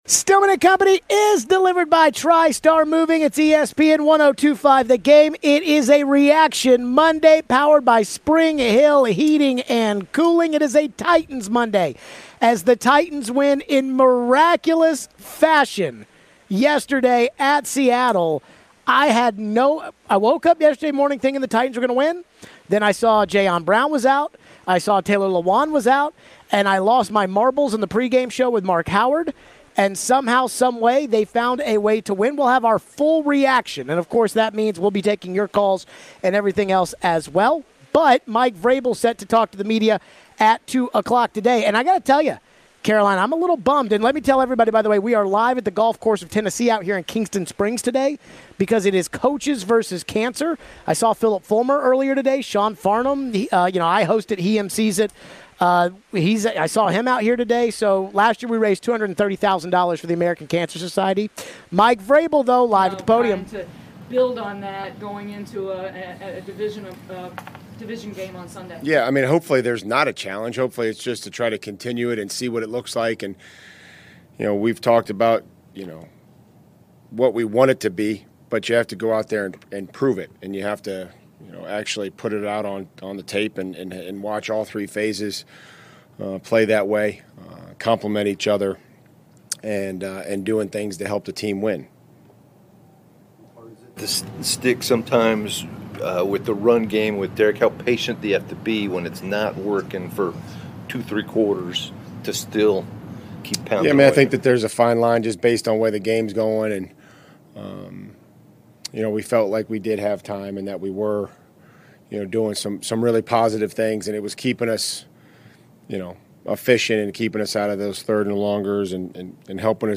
We hear a little of Vrabel's press conference live to start the show.
What did we think about the huge game for Derrick Henry? What was the biggest reason for the win? Plus we take your calls on a reaction Monday.